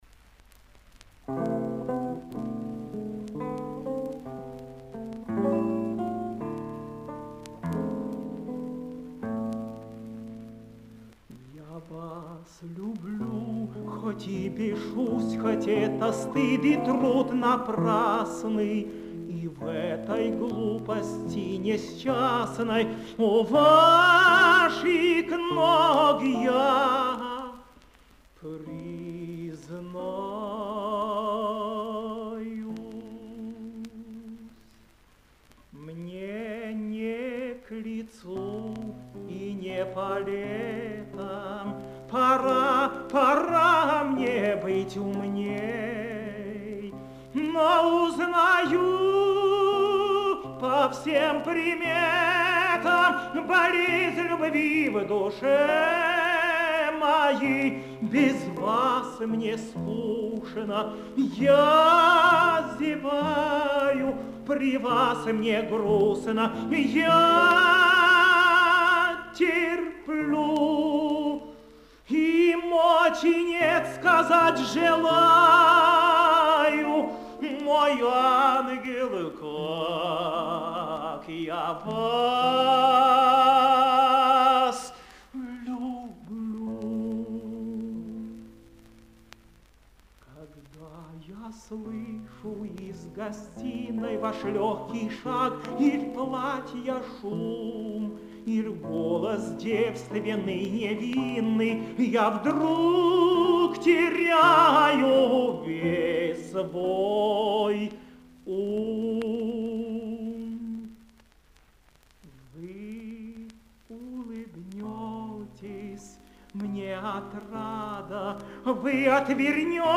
Романс «Признание». Исполняет И. С. Козловский. Партия гитары – А. М. Иванов-Крамской.